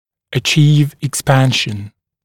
[ə’ʧiːv ɪk’spænʃn] [ek-][э’чи:в ик’спэншн] [эк-]добиваться расширения